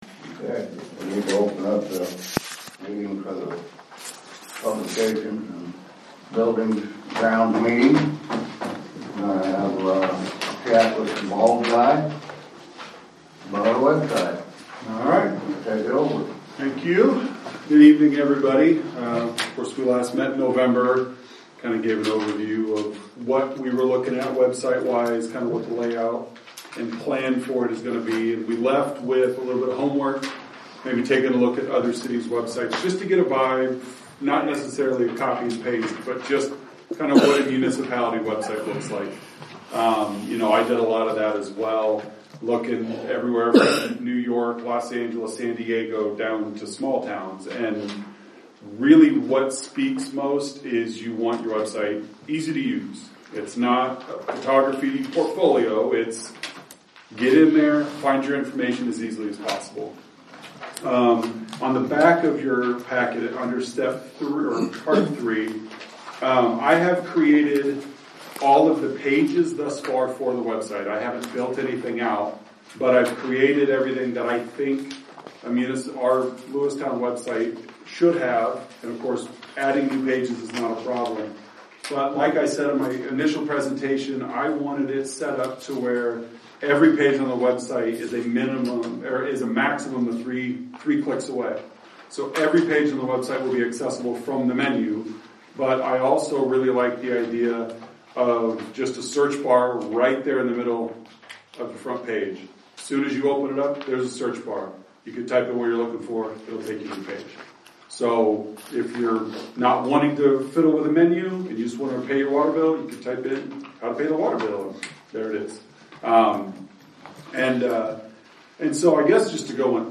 April 22nd, 2025 City Council Meeting Audio